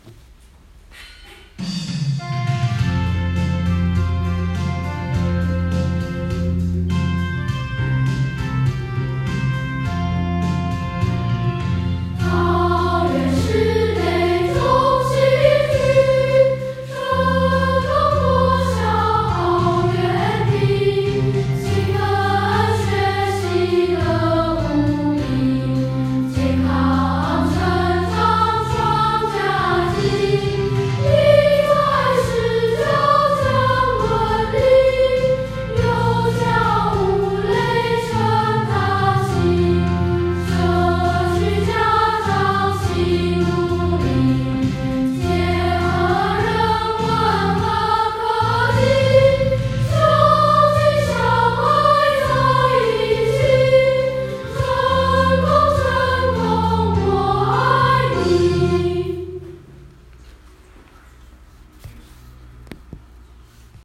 校歌合唱團版.m4a